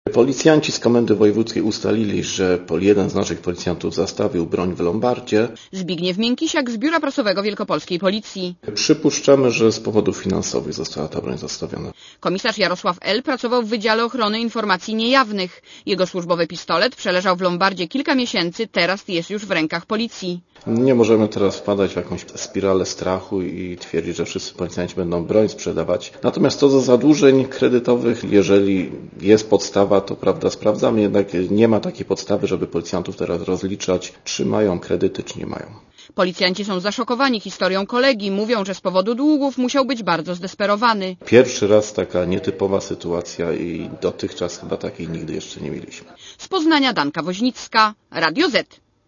Źródło: RadioZet Relacja reportera Radia ZET Oceń jakość naszego artykułu: Twoja opinia pozwala nam tworzyć lepsze treści.